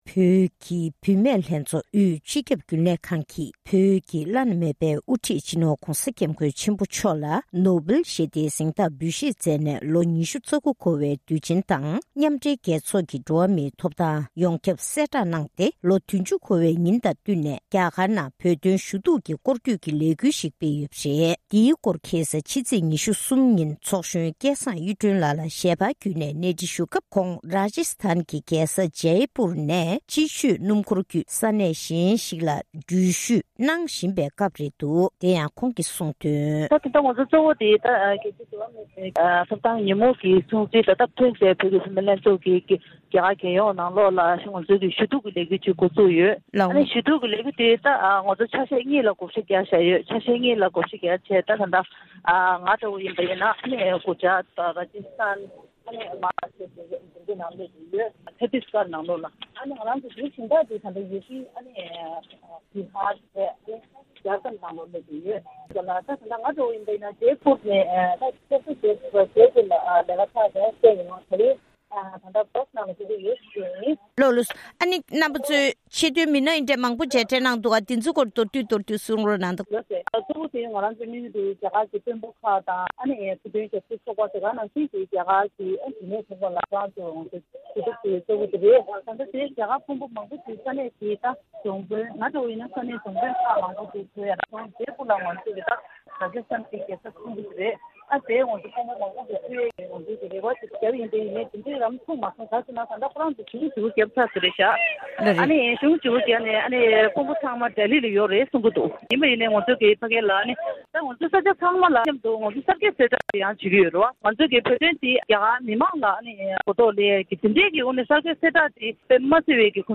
ཞལ་པར་བརྒྱུད་ནས་གནས་འདྲི་ཞུས་པ་ཞིག་ལ་གསན་རོགས་གནང༌།།